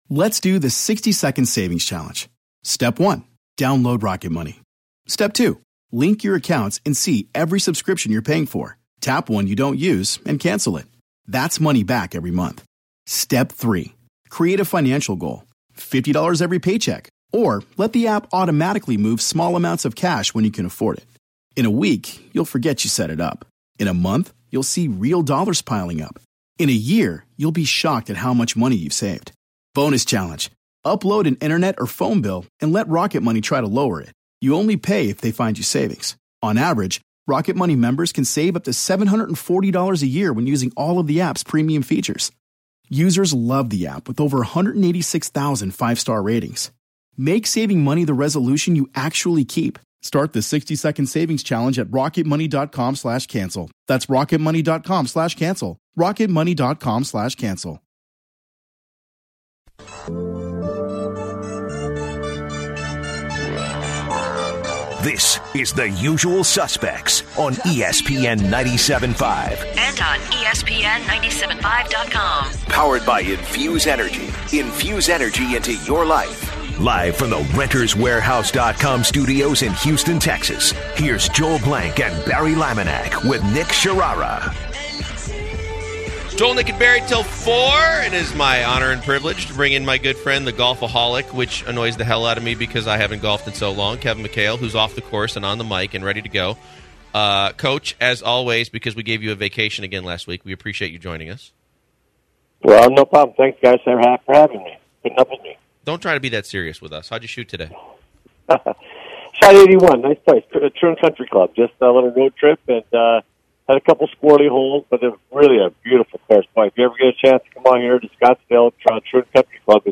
Kevin McHale Interview on The Usual Suspects